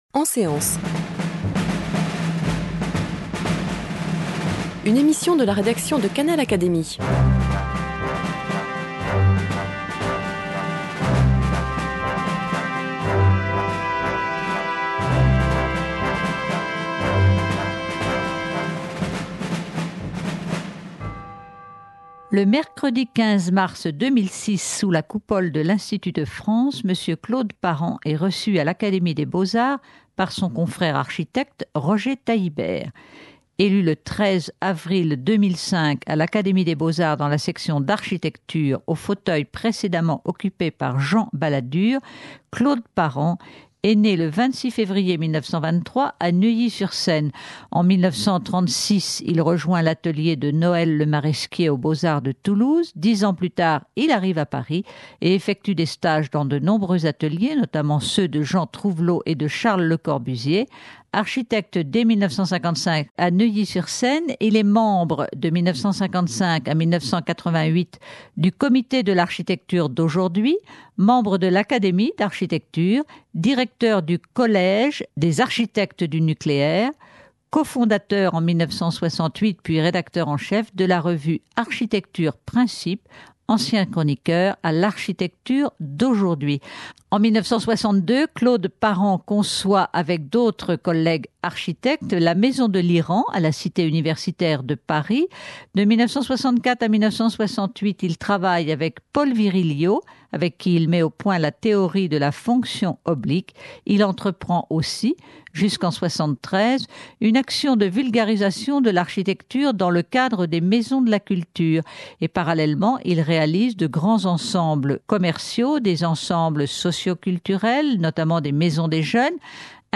Au cours de sa séance hebdomadaire du 13 avril 2005, l’Académie des beaux-arts a élu Claude Parent dans la section d’architecture au fauteuil précédemment occupé par Jean Balladur. Voici, un an après son élection, la réception de Claude Parent sous la Coupole.